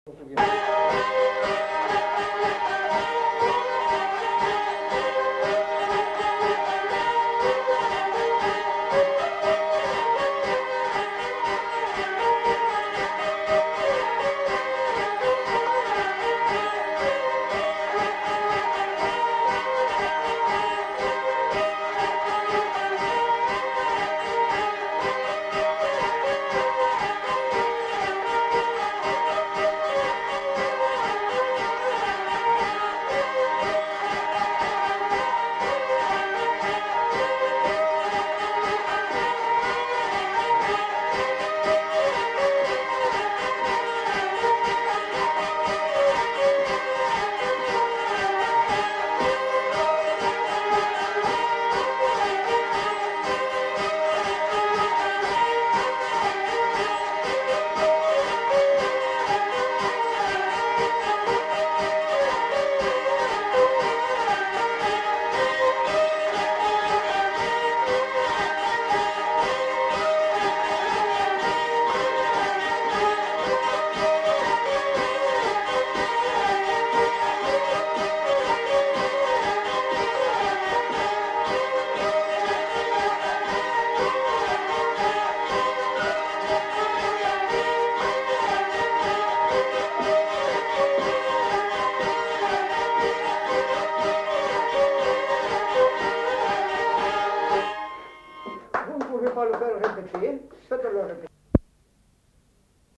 Aire culturelle : Bas-Armagnac
Lieu : Mauléon-d'Armagnac
Genre : morceau instrumental
Instrument de musique : vielle à roue
Danse : polka piquée